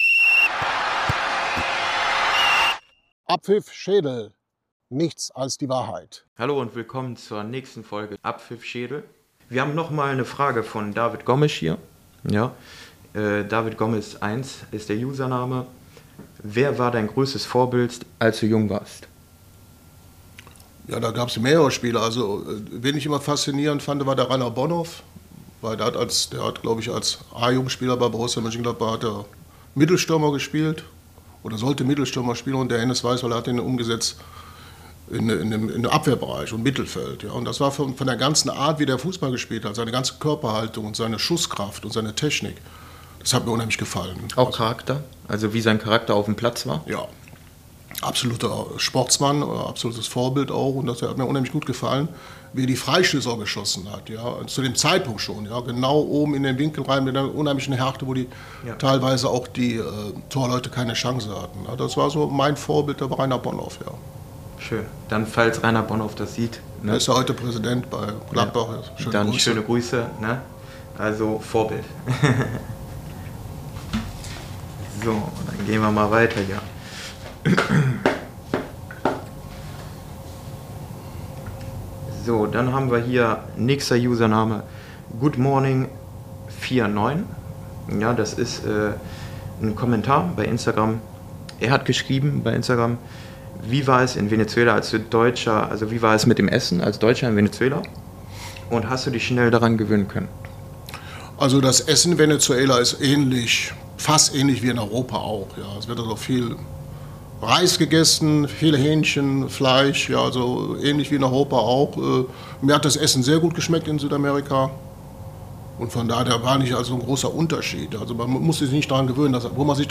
liest und beantwortet Fragen, die ihm Fans gestellt haben. YouTube -Es geht um Erlebnisse, Erinnerungen und Anekdoten aus seiner aktiven Zeit als Profi.